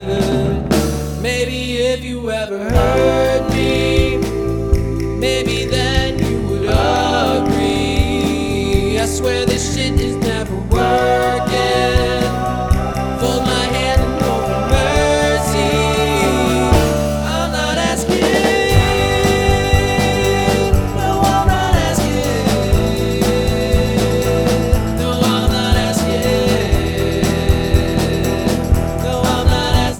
Drums
Bass
trumpet and flugelhorn